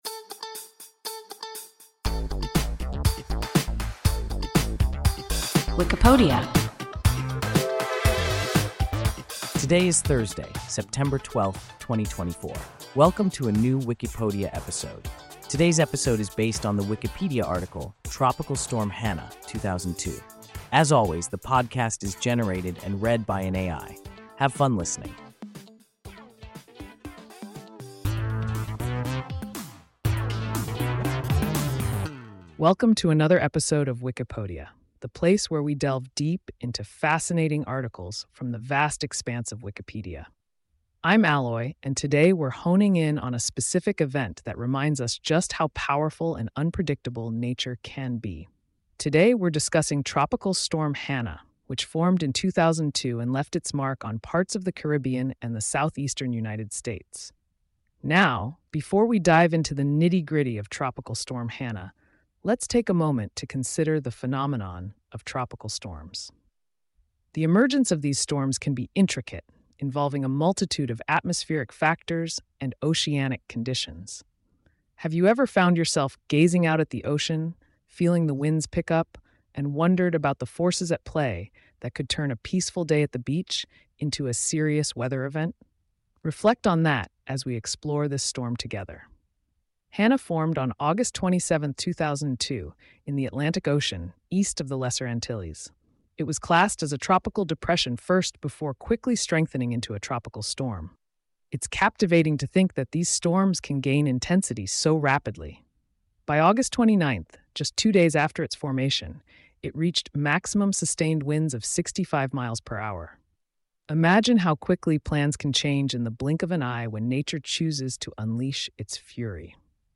Tropical Storm Hanna (2002) – WIKIPODIA – ein KI Podcast